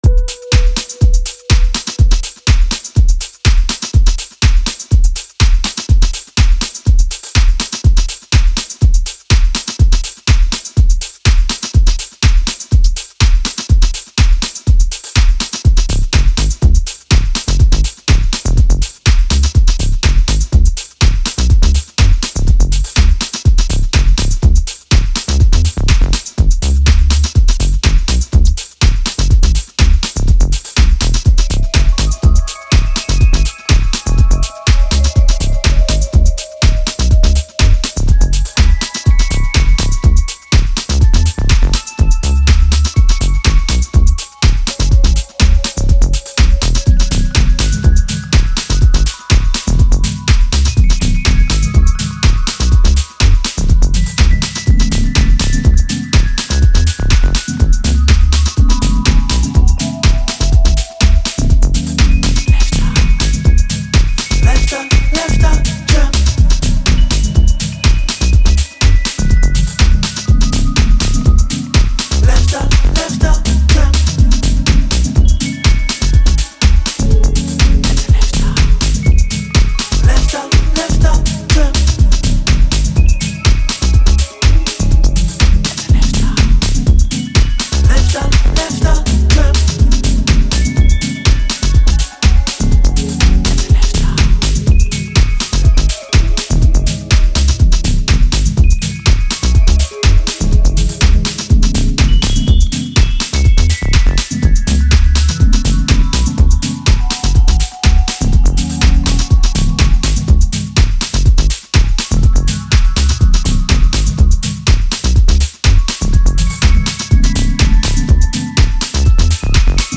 Broken Beat / Nu-Jazz